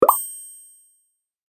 Download Free Motion Graphics Sound Effects | Gfx Sounds
Sale-pop-up-purchase-alert-3.mp3